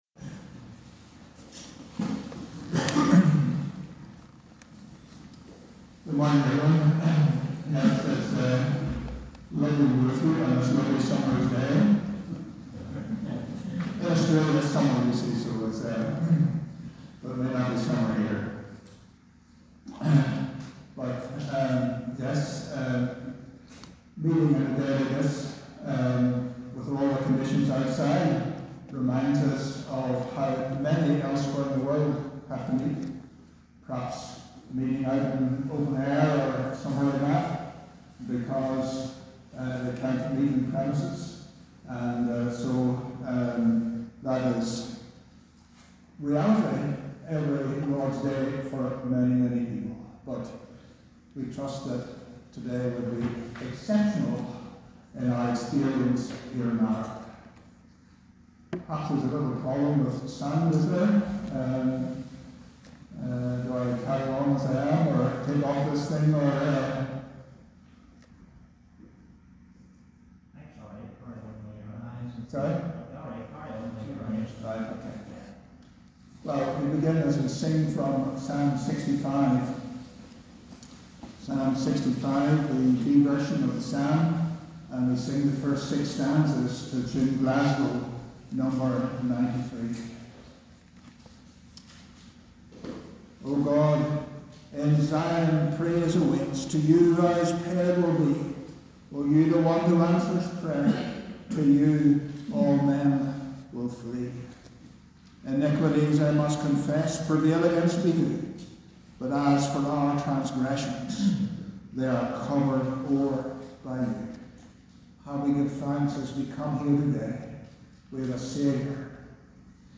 Apologies about the quality we were meeting in the hall due to power cut.
Morning Service Apologies about the quality we were meeting in the hall due to power cut.